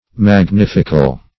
Meaning of magnifical. magnifical synonyms, pronunciation, spelling and more from Free Dictionary.
Search Result for " magnifical" : The Collaborative International Dictionary of English v.0.48: Magnific \Mag*nif"ic\, Magnifical \Mag*nif"ic*al\, a. [L. magnificus; magnus great + facere to make: cf. F. magnifique.